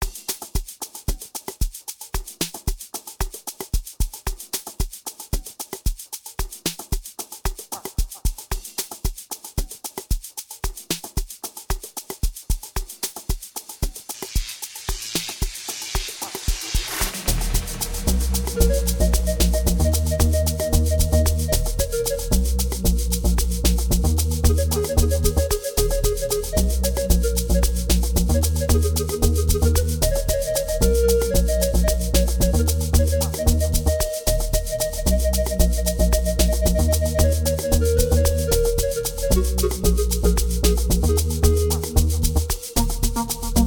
00:43 Genre : Amapiano Size